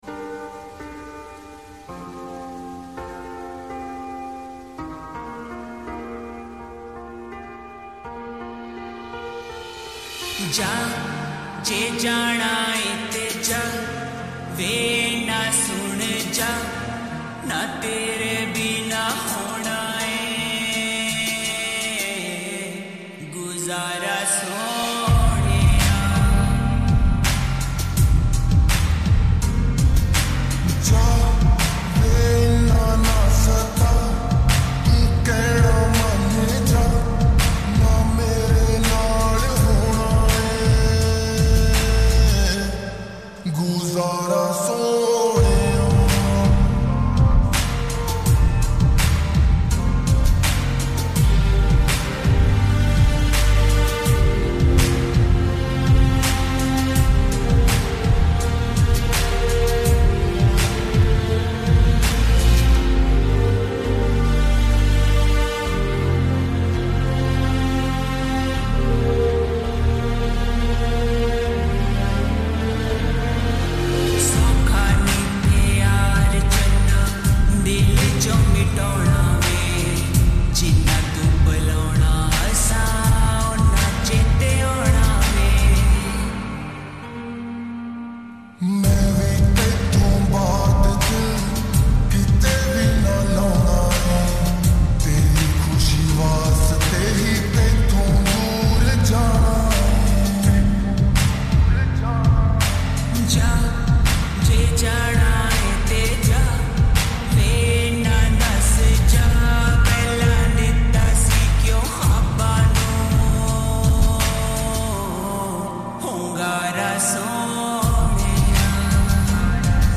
Full Song Slowed And Reverb
old Sad song